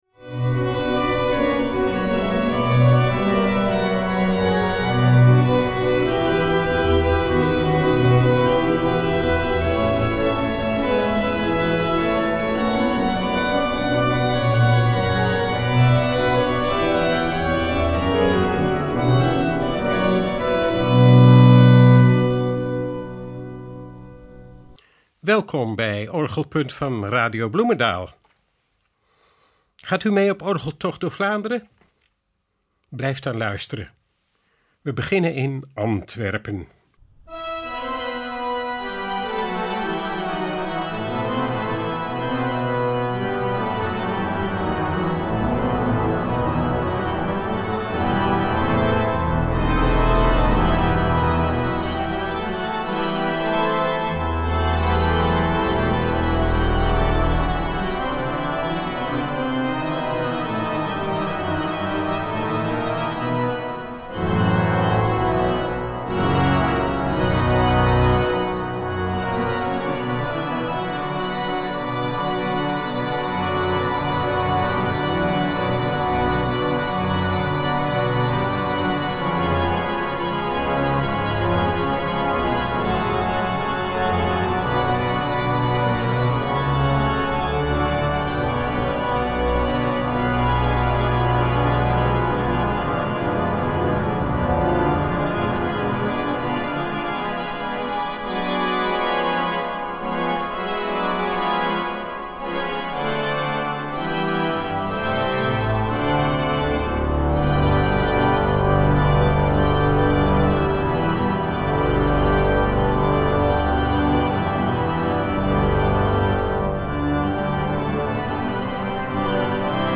In deze uitzending horen we orgels die tijdens de excursies op het programma staan, in Antwerpen, Turnhout, Herentals, Averbode en Brussel (foto boven).
Vanzelfsprekend met Belgische muziek, maar ook al improviserend.